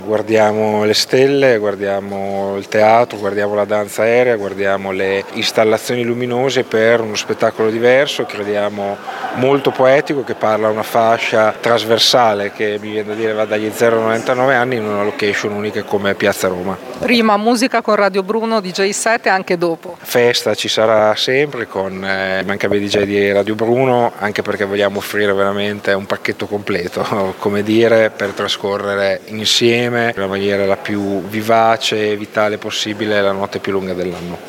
L’assessore alla Cultura Andrea Bortolamasi: